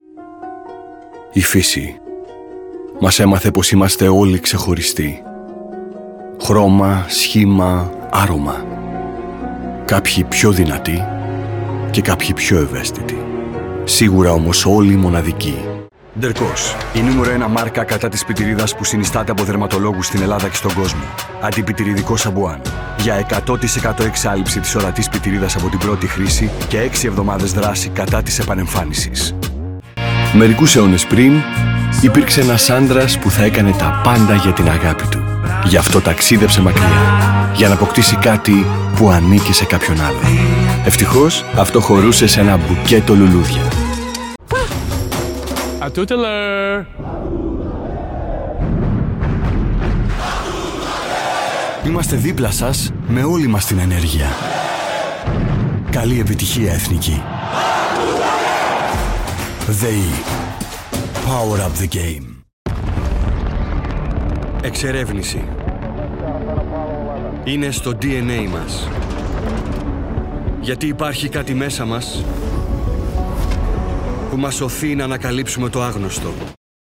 Greek, Male, Home Studio, 20s-40s